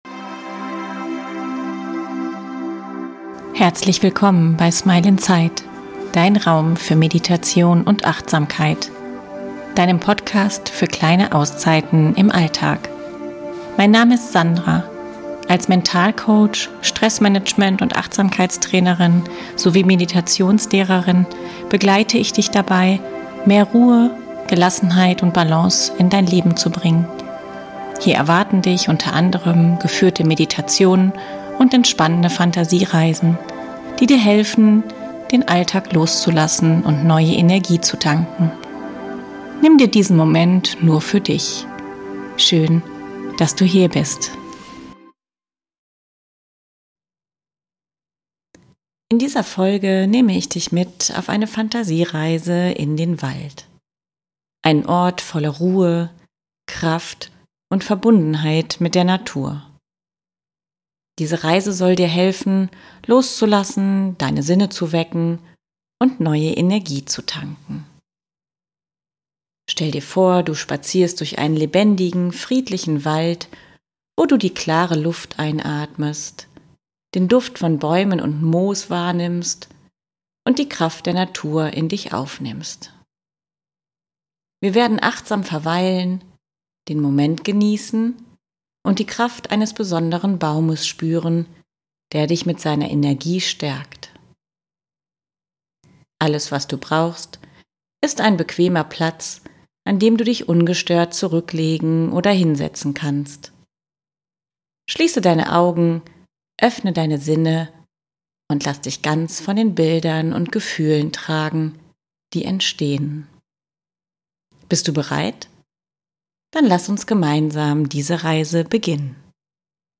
In dieser Folge nehme ich dich mit auf eine Fantasiereise in den Wald – einen Ort voller Ruhe, Kraft und Verbundenheit mit der Natur. Diese Reise soll dir helfen, loszulassen, deine Sinne zu wecken und neue Energie zu tanken.